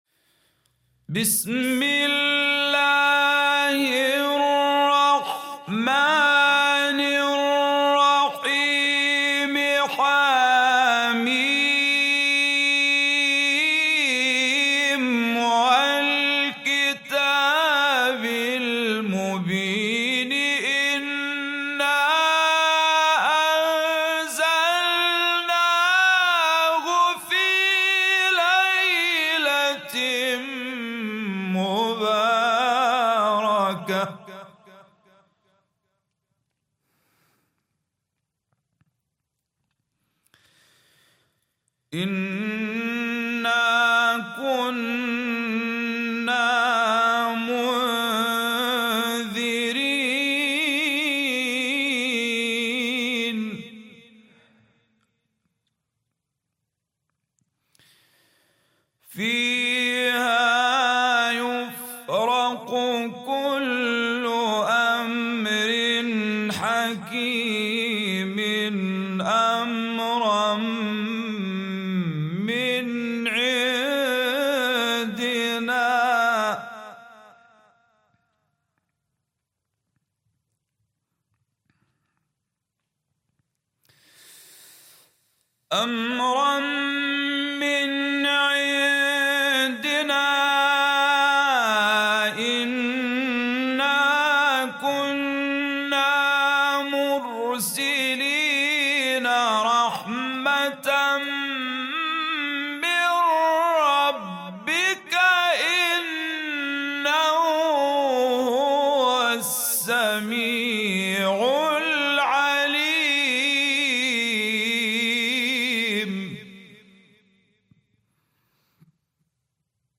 An Epic Quran recitation to welcome Laylat-Al Qadr.mp3